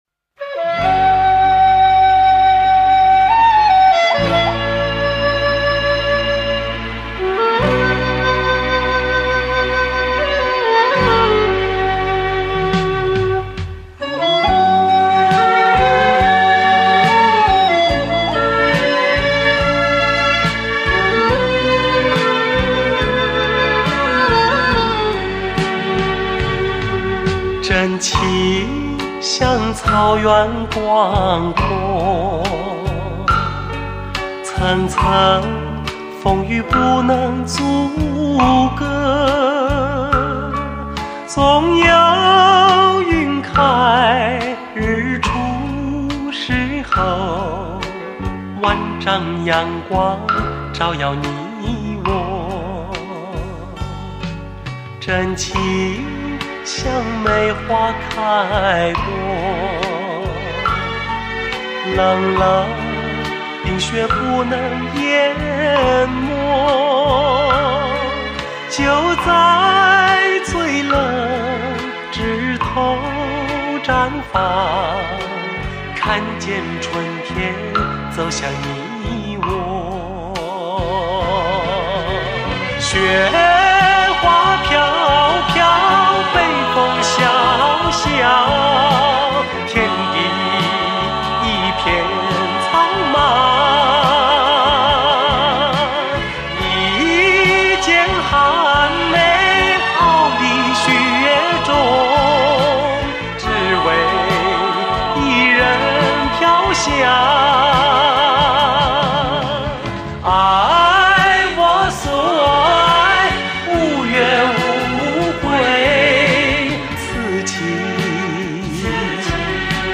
来自全球领导地位?音室的崇高评价24BIT数位录音真空管麦克风真实收录，醉人嗓音完美呈现，发烧典藏珍品！